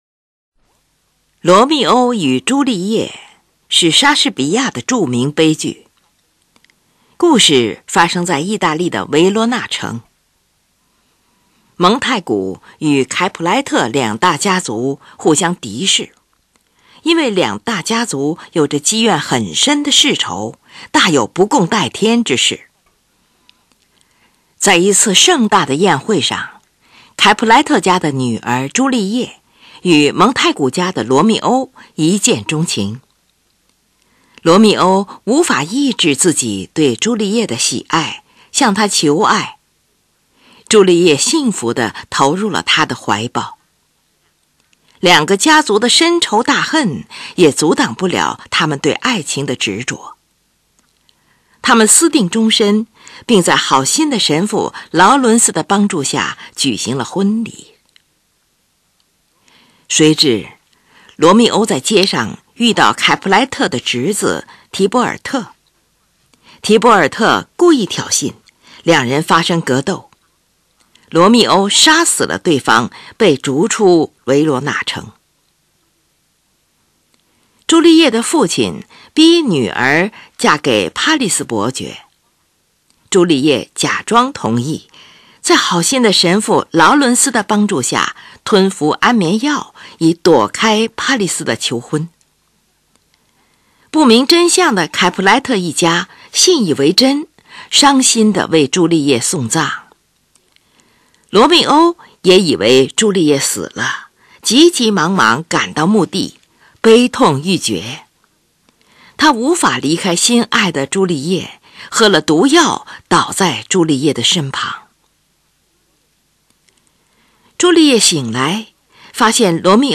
这里选用组曲中九个片段：《蒙太古与凯普莱特》（《争斗》、《械斗》）——这是由舞剧第一幕第一场“维罗纳亲王的命令”和第二场“骑士之舞”两段音乐组成。“亲王的命令”这段音乐很短，但形象鲜明。
这个主题的节奏坚定有力、呆板阴暗而又庄严有力。